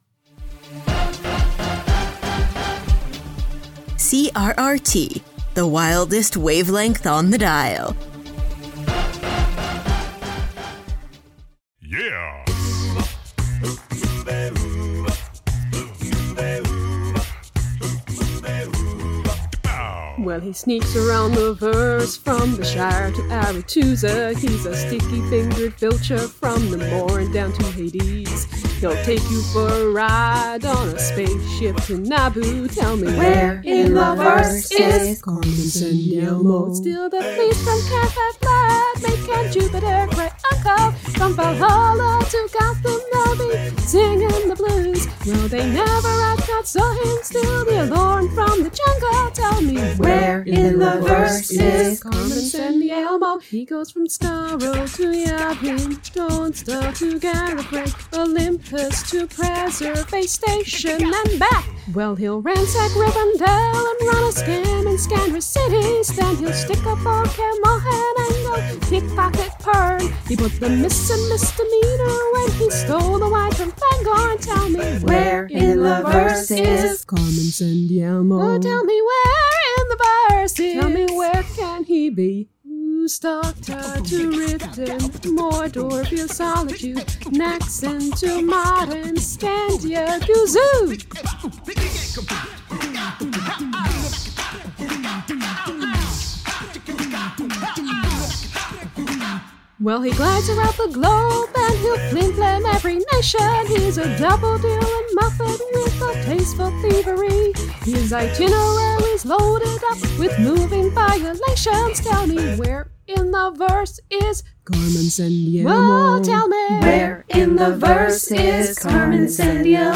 info: collaboration|ensemble